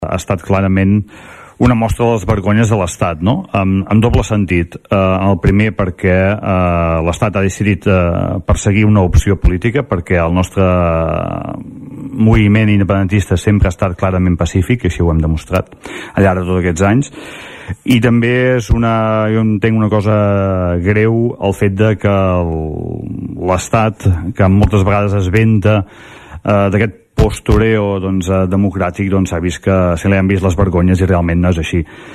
El ple de l‘Ajuntament de Tordera va aprovar una moció a favor de demanar al Congrés Espanyol la investigació del CatalanGate, un “espionatge il·legal de l’Estat contra els líders independentistes” que ha destapat el diari “New Yorker”.